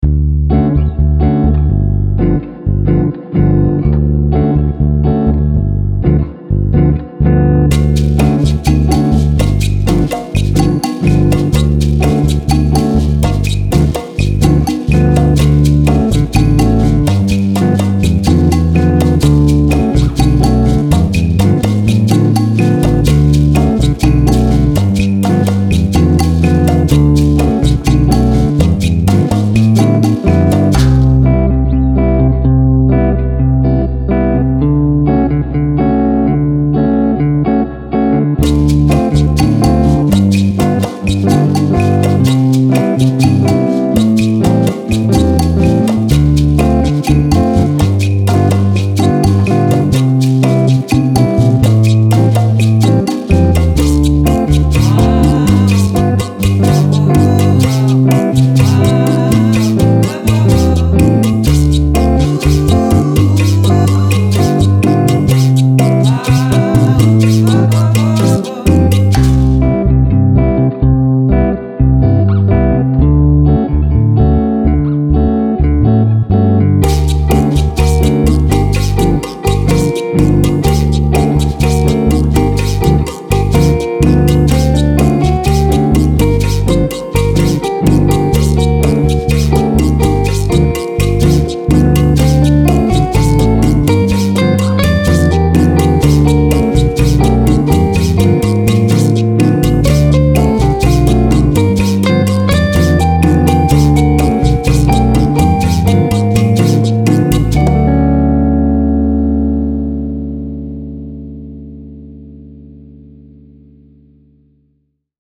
Style Style Jazz, World
Mood Mood Calming, Relaxed
Featured Featured Bass, Electric Guitar, Percussion +1 more
BPM BPM 125